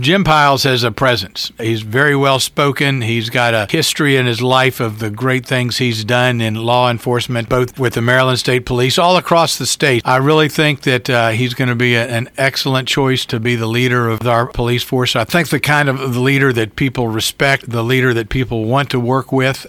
Mayor Ray Morriss tells WCBC that Pyles brings a mature background in law enforcement and familiarity with the area…